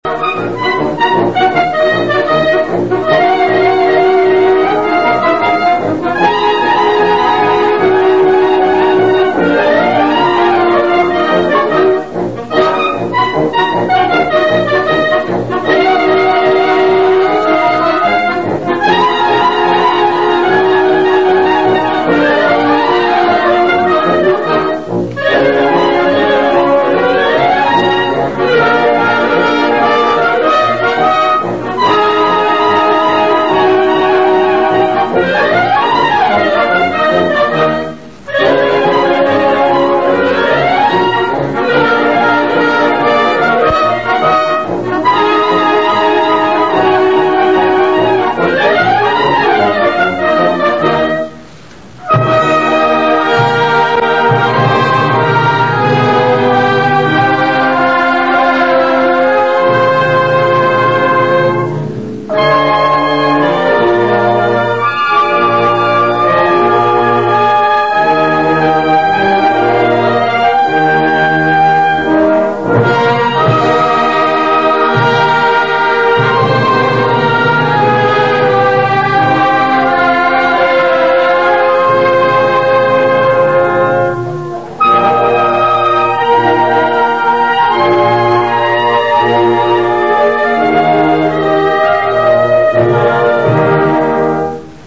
Ascolta Registrazione dal vivo anni 80 Teatro Oriente - Torre del Greco Se non si ascolta subito la musica attendere qualche secondo solo la prima volta.